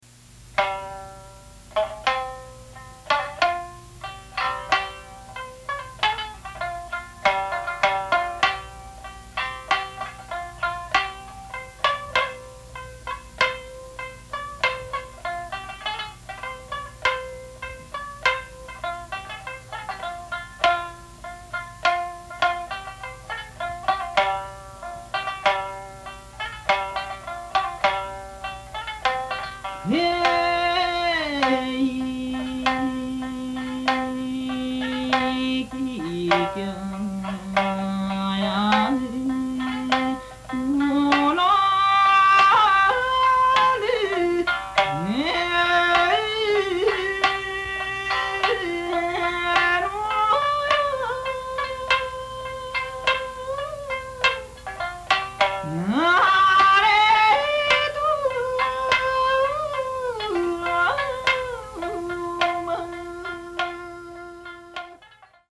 全16曲　昭和37年に収録したものです
唄・三線